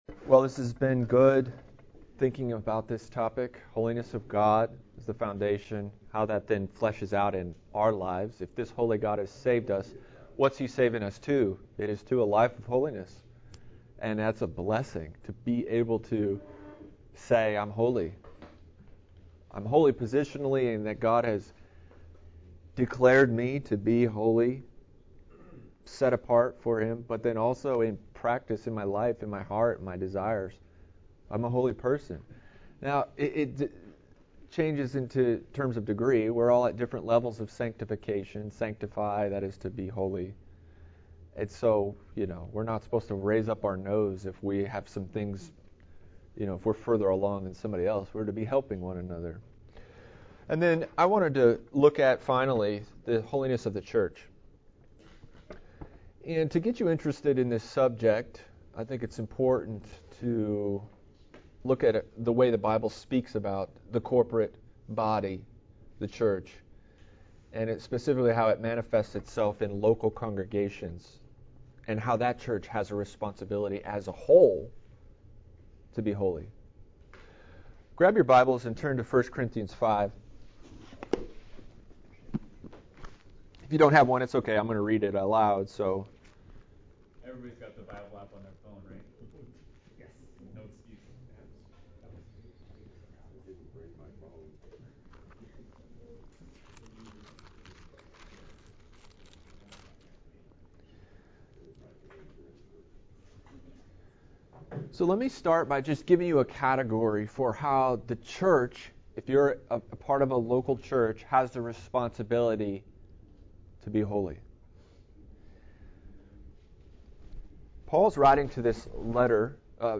at the 2021 CBC Men’s Retreat